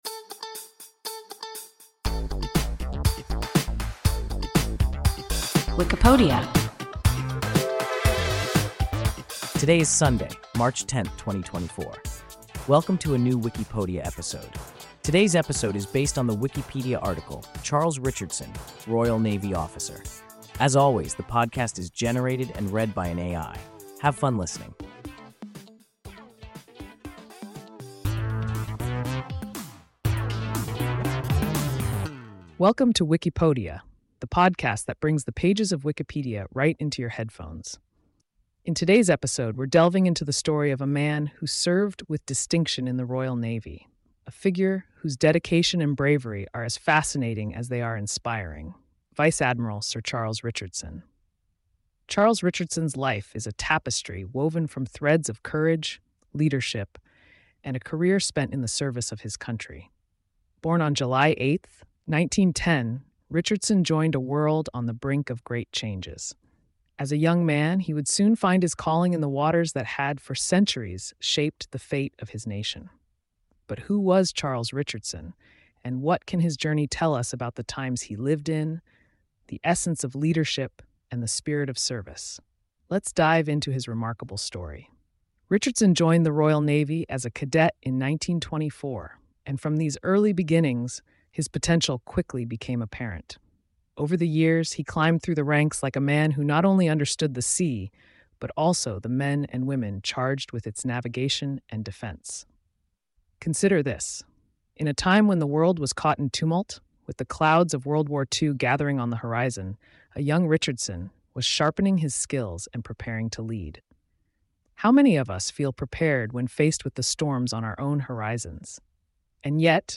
Charles Richardson (Royal Navy officer) – WIKIPODIA – ein KI Podcast